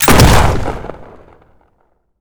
gun_shotgun_shot_04.wav